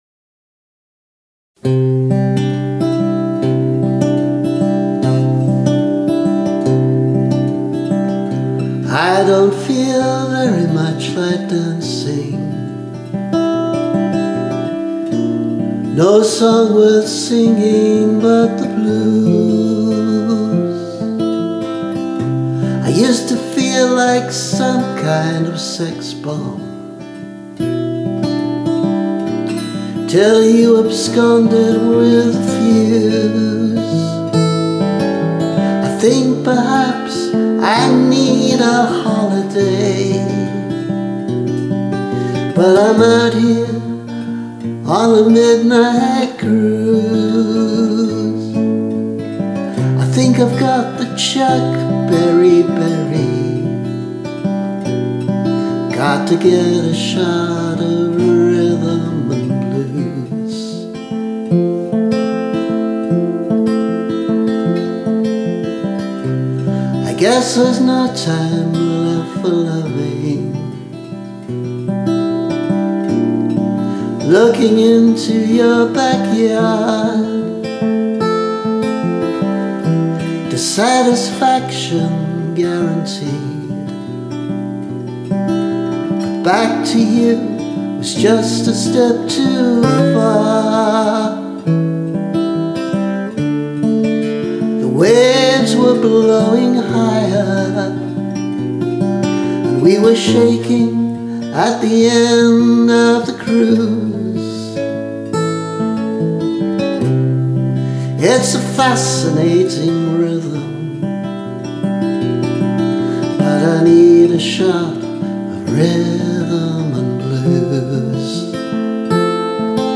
Chuck Berry-beri [less rough demo]